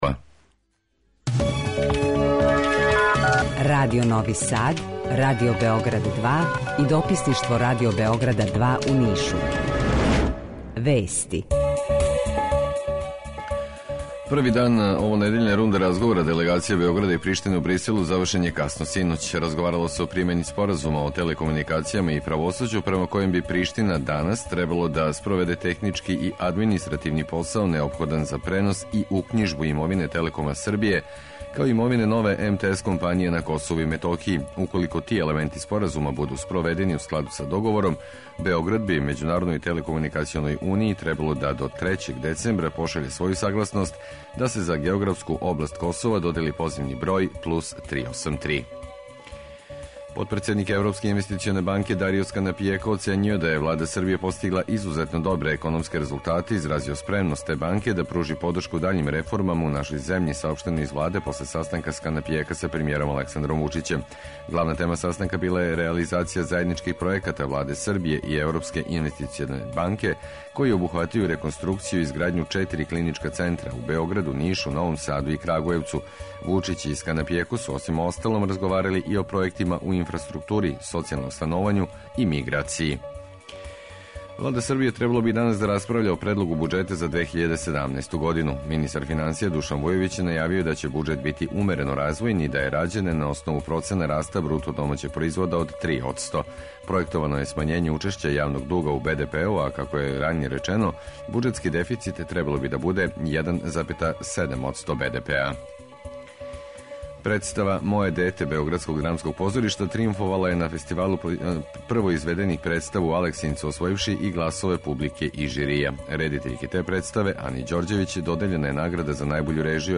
У два сата ту је и добра музика, другачија у односу на остале радио-станице.